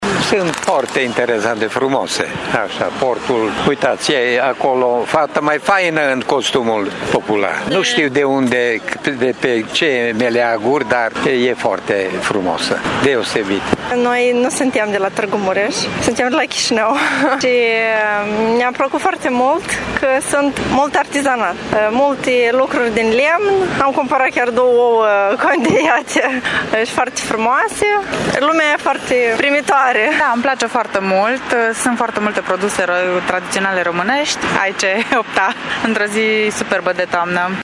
În Piața Trandafirilor din Tîrgu-Mureș și-au expus arta populară și produsele tradiționale peste 80 de meșteri populari și producători din toată țara și din Republica Moldova. Târgumureșenii sunt bucuroși că au posibilitatea să viziteze și să cumpere produse direct de la producători: